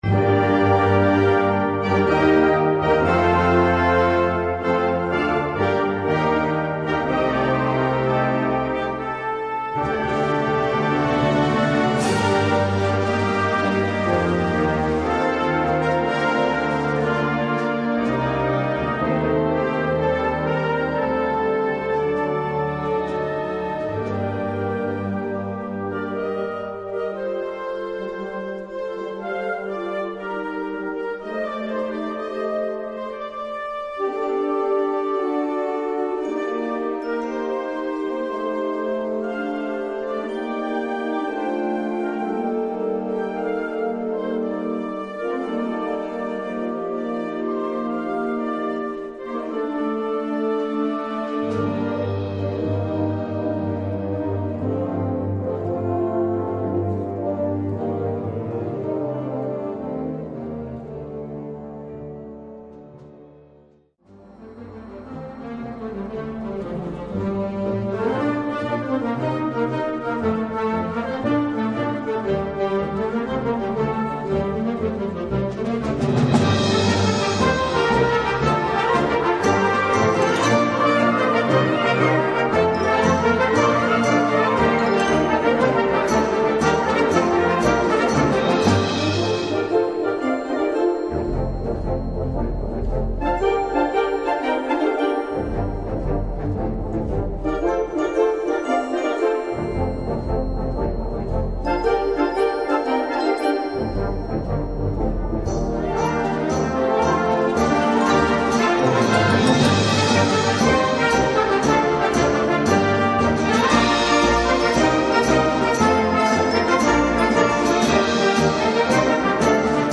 Gattung: Rhapsody for Band
Besetzung: Blasorchester